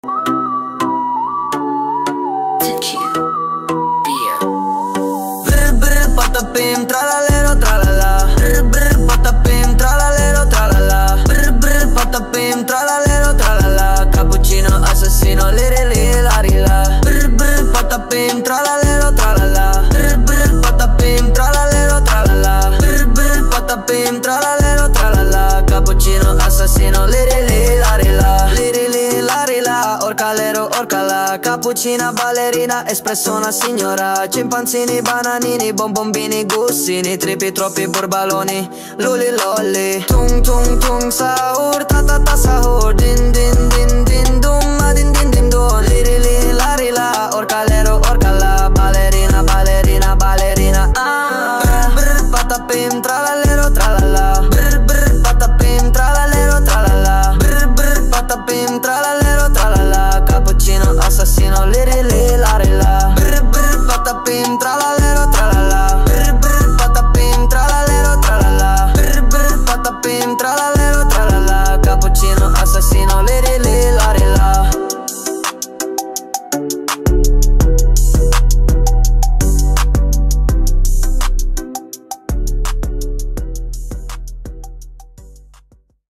Brainrot_rap.mp3.ogg